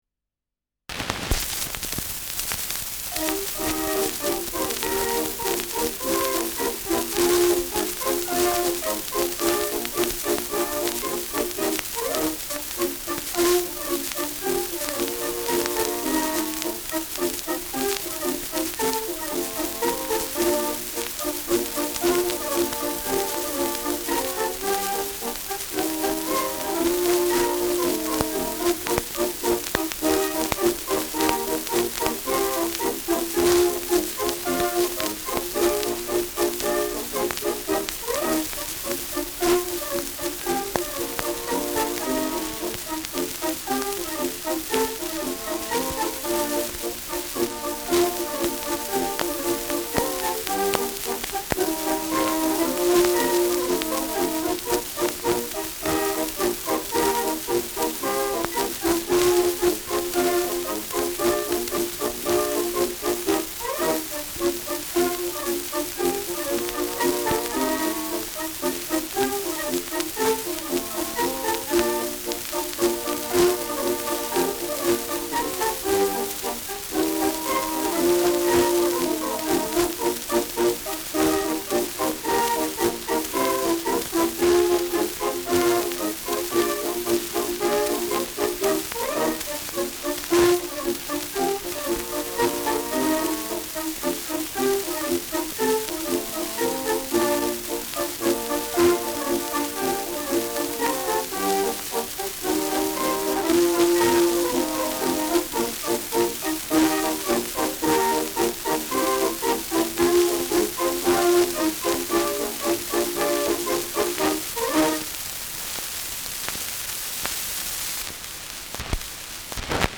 Schellackplatte
Abgespielt : Stark verrauscht : Leise Aufnahme : Nadelgeräusch : Gelegentlich leichtes Knacken
Militärmusik des k.b. Leib-Regiments, München (Interpretation)
[München] (Aufnahmeort)